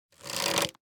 Minecraft Version Minecraft Version snapshot Latest Release | Latest Snapshot snapshot / assets / minecraft / sounds / item / crossbow / quick_charge / quick2_2.ogg Compare With Compare With Latest Release | Latest Snapshot